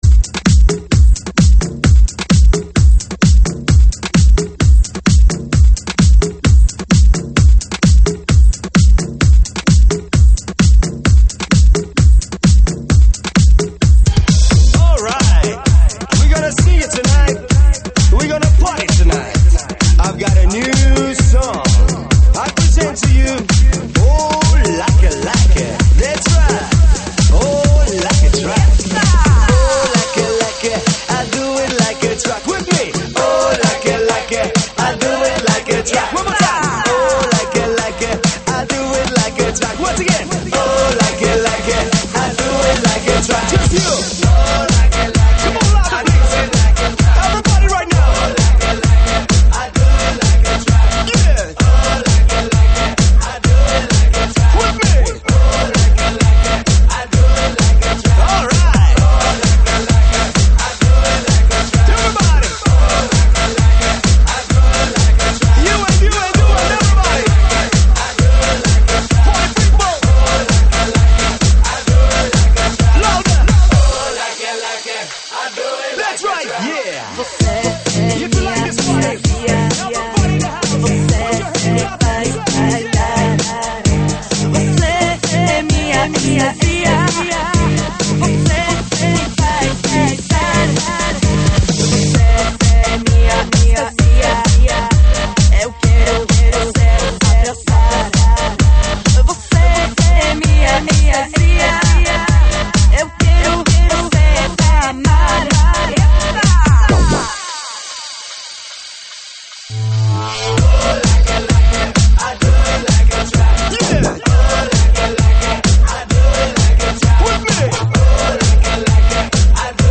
上传于：2025-01-10 22:27，收录于(慢摇舞曲)提供在线试听及mp3下载。